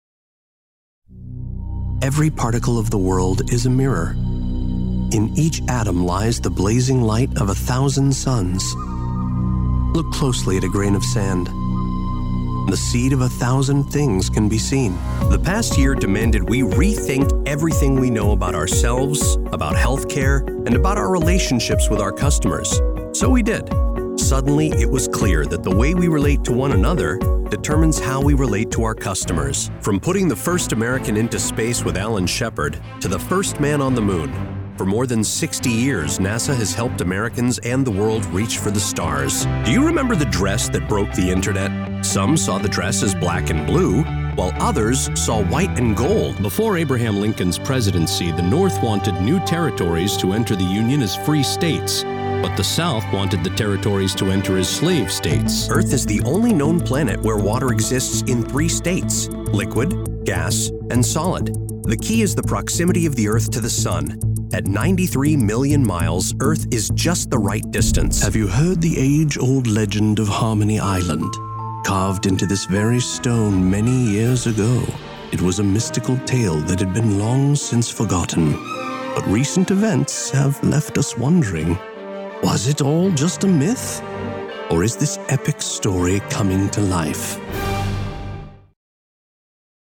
Male
Character, Confident, Corporate, Deep, Friendly, Gravitas, Natural, Wacky, Versatile
General American (native), Southern American, New York American, Boston American
He has a tremendous vocal range and excellent comedic timing.
Microphone: Sennheiser MKH 416
Audio equipment: Private, Source-Connect Certified studio, featuring wired, business-class gigabit fiber internet, and a professionally treated recording booth.